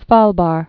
(svälbär)